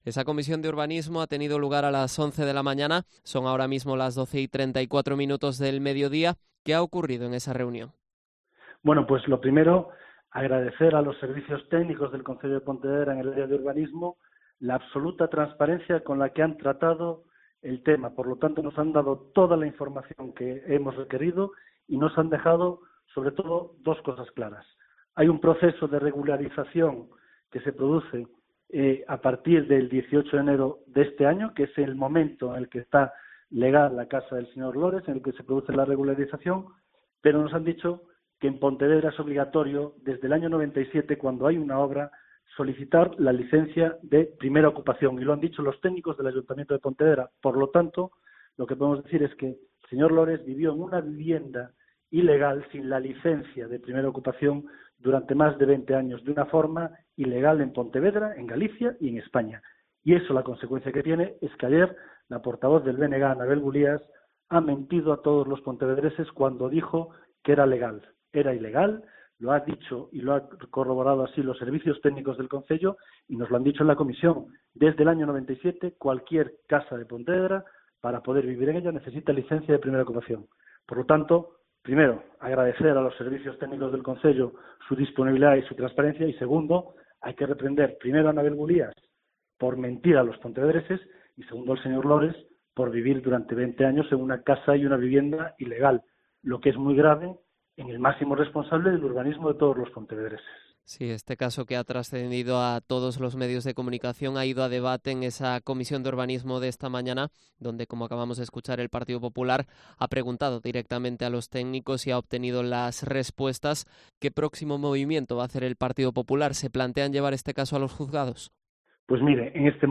Entrevista a Rafa Domínguez, presidente del PP de Pontevedra